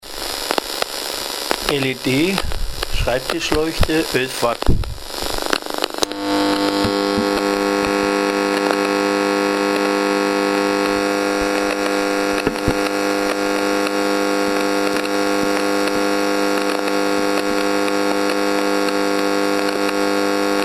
STÖRQUELLEN AUDIODATENBANK
Leuchte OBI k.A OBI LED Leuchtmittel 11W Low E-Field Netz im Betrieb direkte Brührung